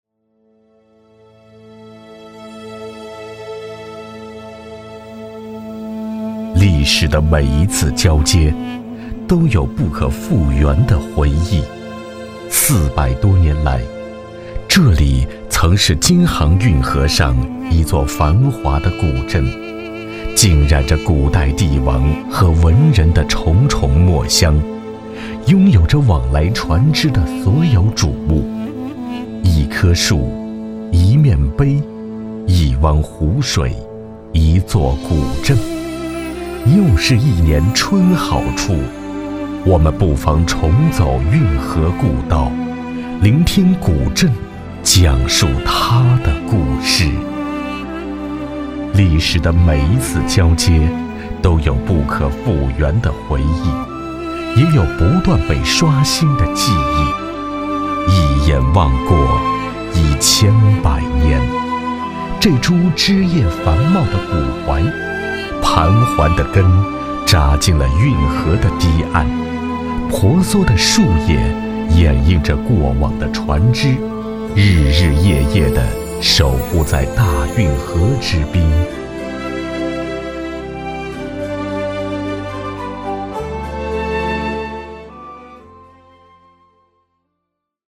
专注高端配音，拒绝ai合成声音，高端真人配音认准传音配音
男83